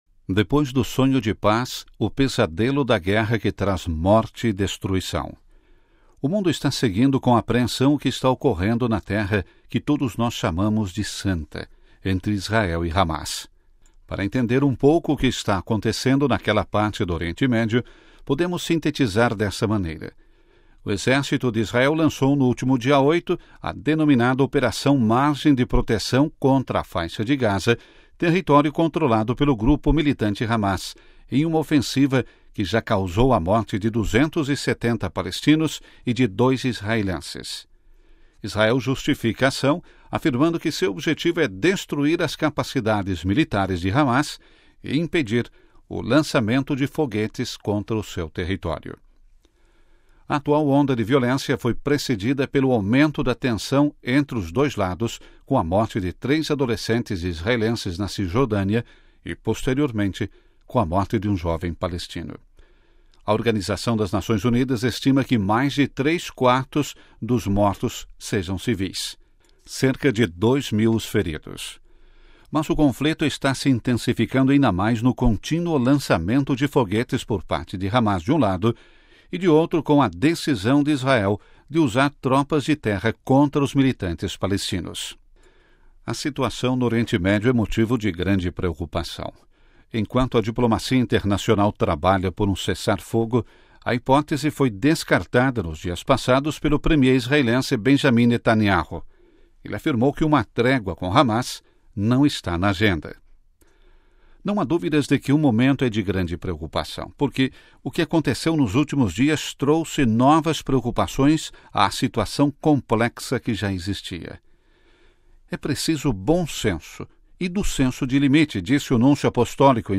Editorial: O desejo de paz